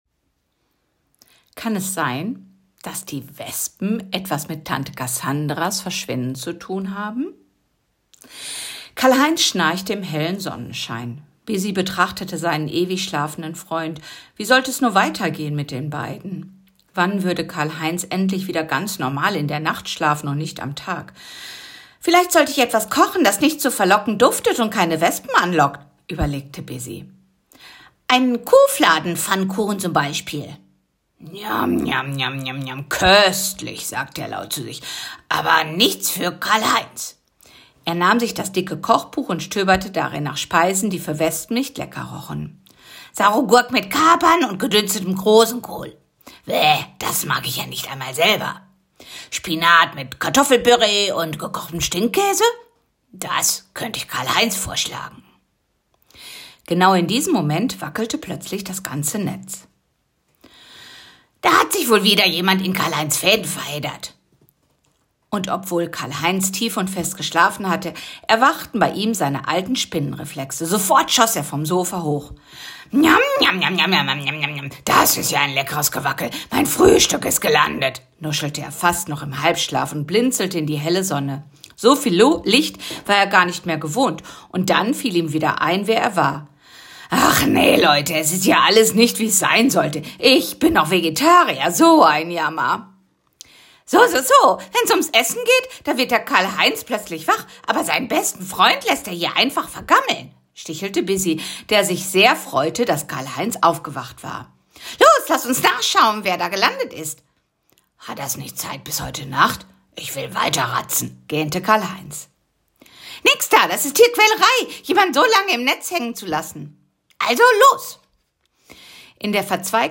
Die Lehrkräfte haben die Geschichte von Karl-Heinz, der Kreuzspinne und seinem Freund, der Stubenfliege Bisy als Lesethater vorgespielt.